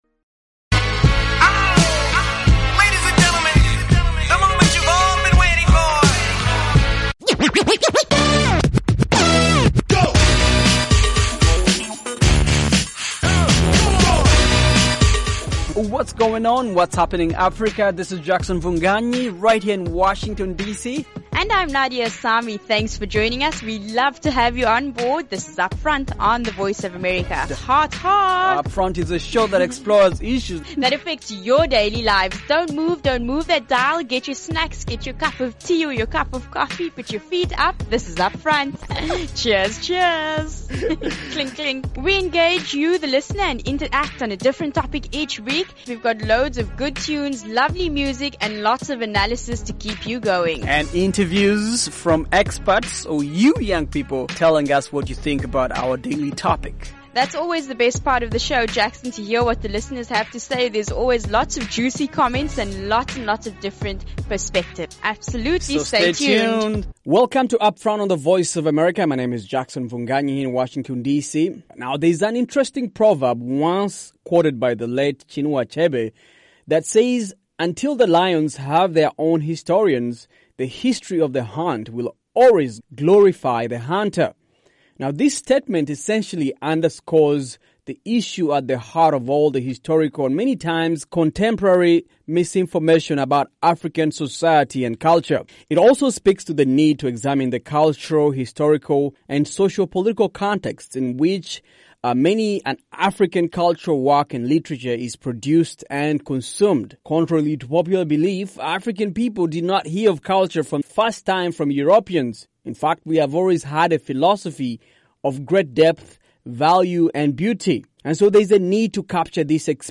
fresh, fast-paced show
talk to teens and young adults